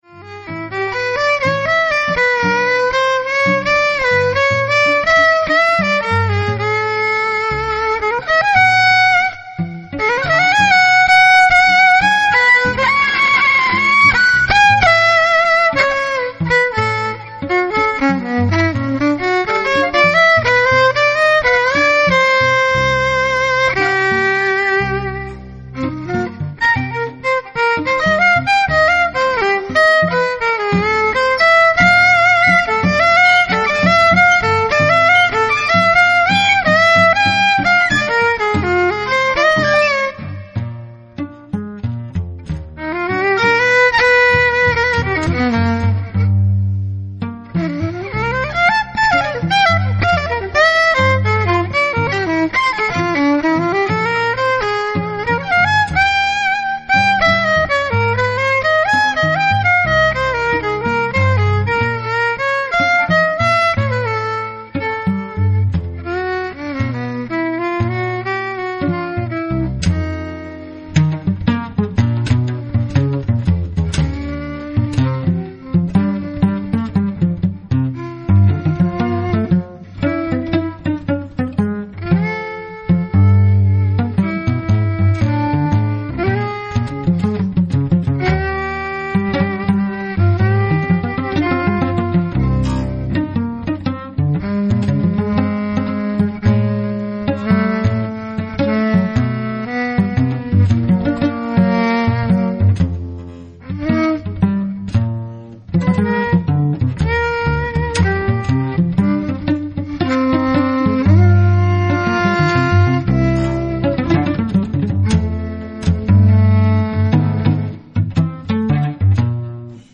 violin, flugelhorn, percussion
cello, sarangi